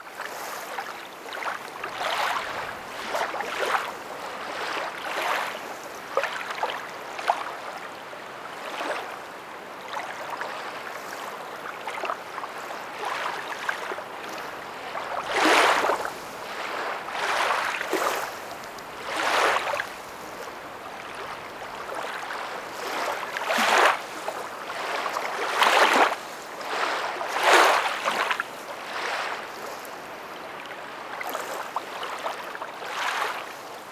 LAKE LAP 00L.wav